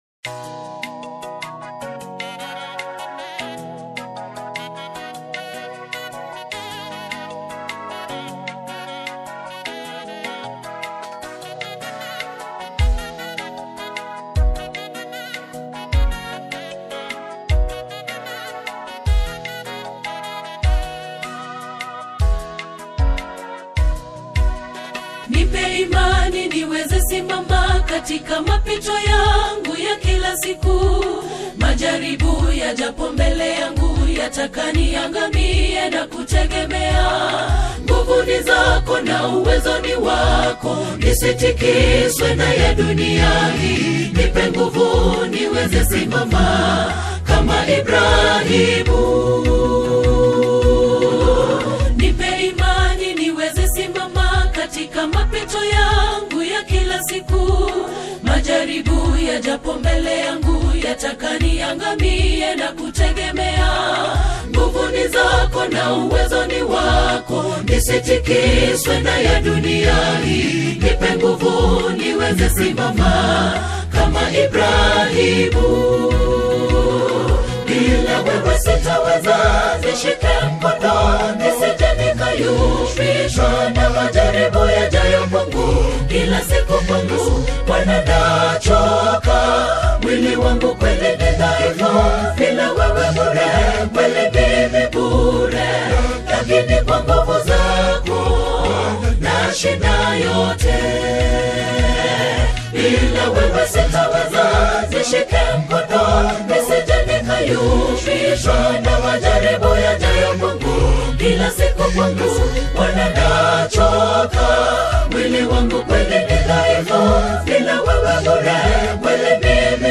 a soul-stirring and prayerful single